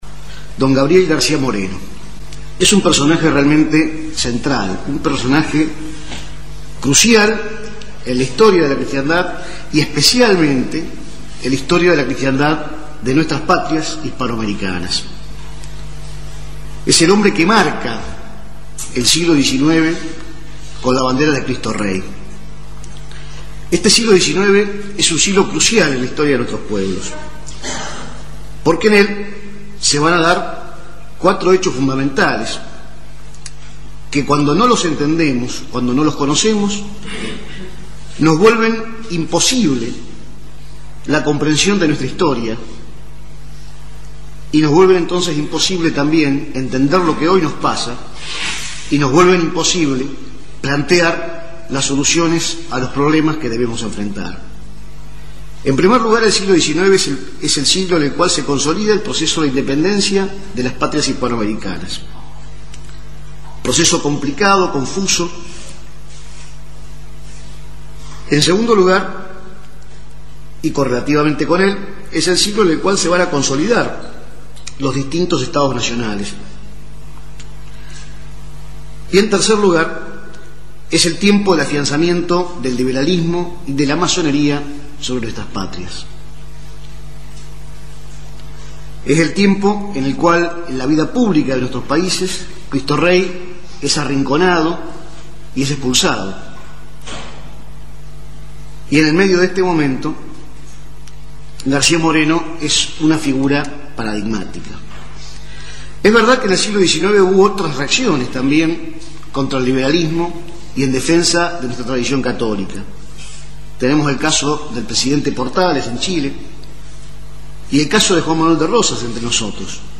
Audio–libro